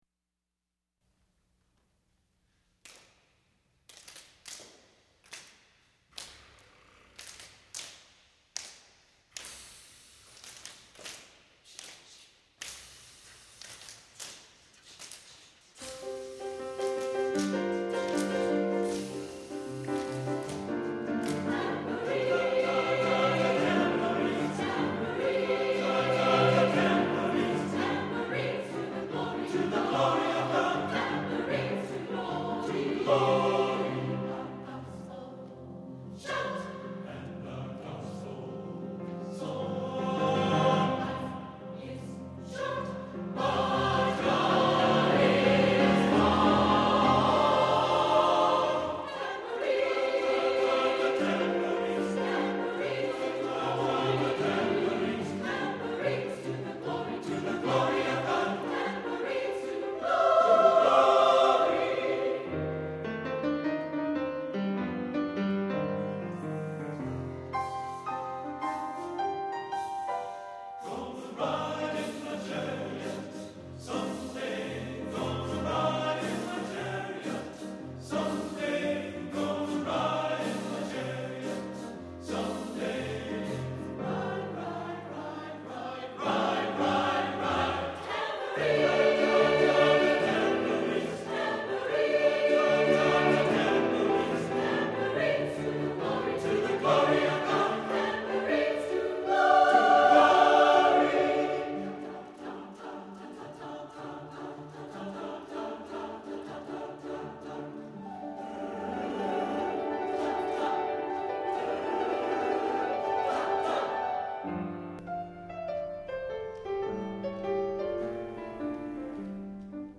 for SATB Chorus and Piano (2000)